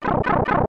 Sound effect from Super Mario RPG: Legend of the Seven Stars
Self-recorded using the debug menu